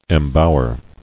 [em·bow·er]